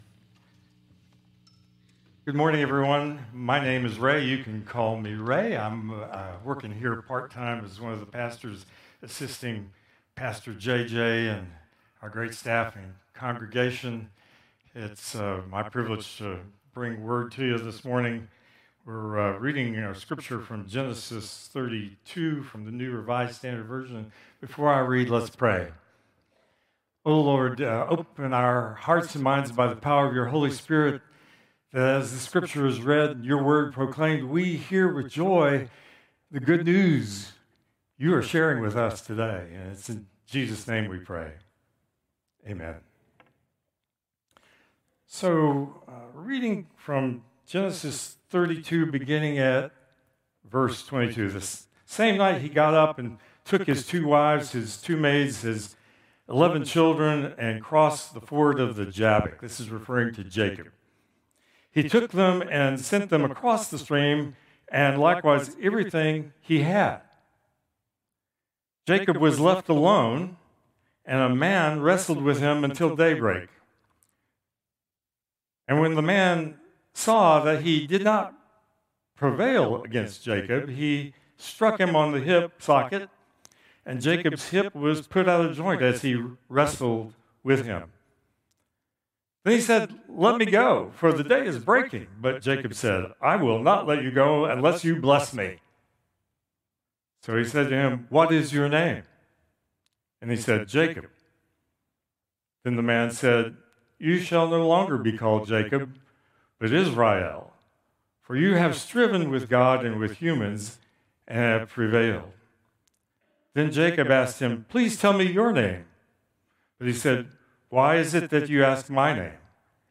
Contemporary Service 9/14/2025